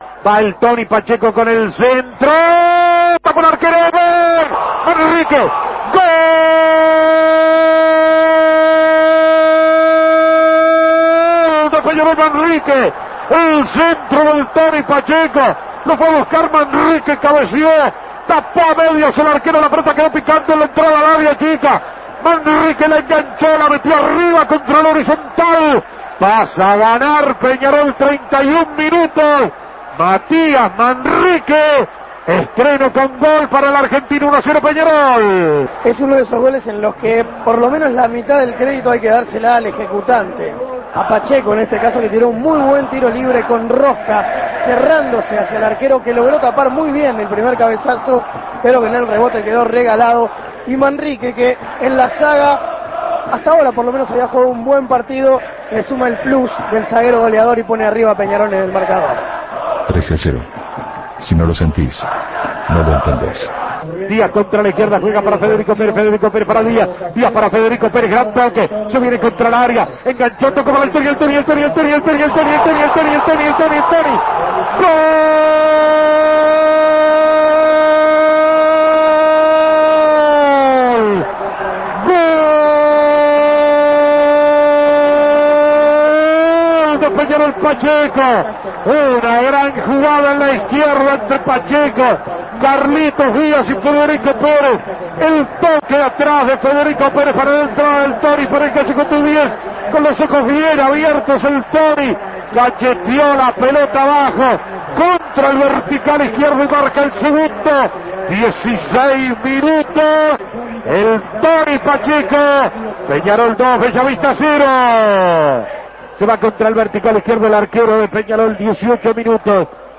Goles y comentarios